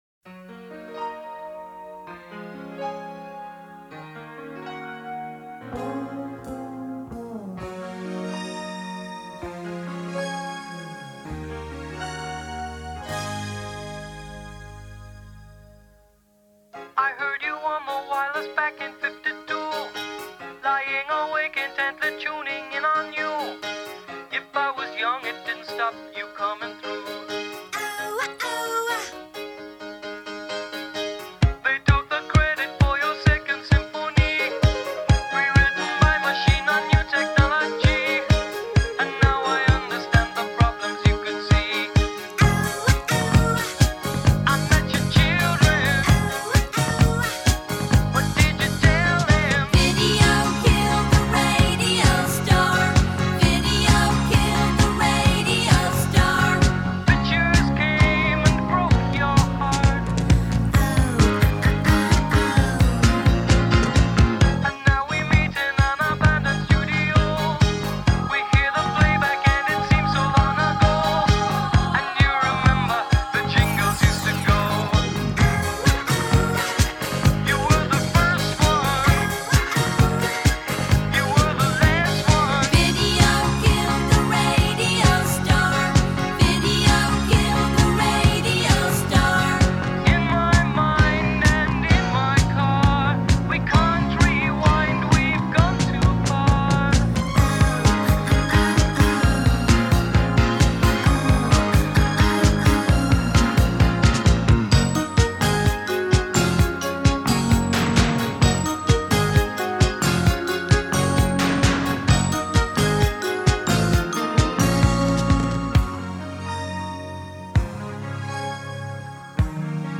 New Wave
lead-singer and guitarist